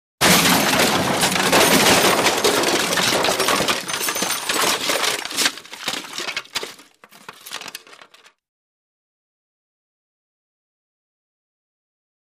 Wood/Glass | Sneak On The Lot
Object Crashing Through Wood And Glass.